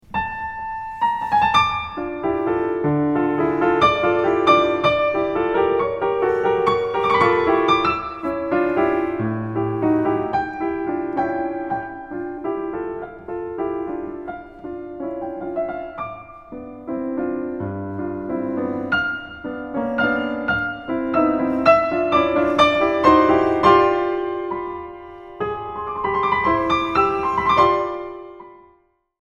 As a contrast, and with no warning whatsoever (which was also a trademark of C.P.E. Bach) Beethoven starts a highly anguished part, like someone feeling good until he realized his wallet is lost…or feel free to add your own metaphor: